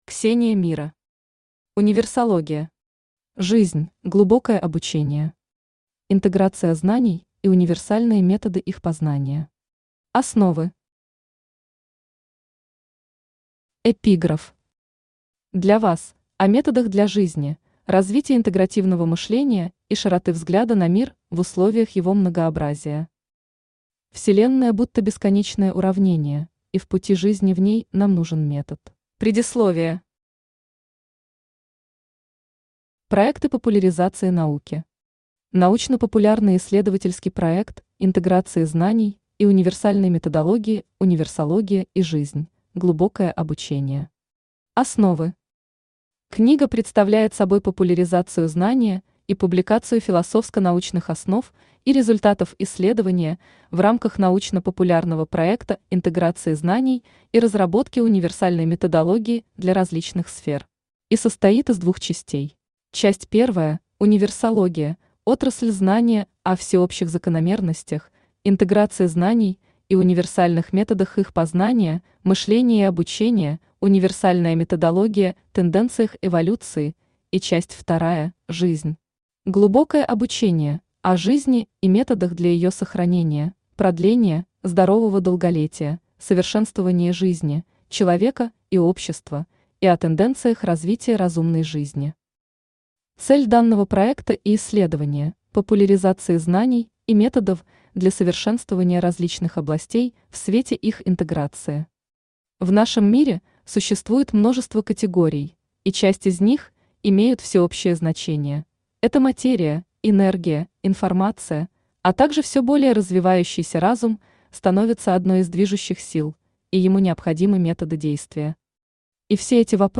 Основы Автор Ксения Мира Читает аудиокнигу Авточтец ЛитРес.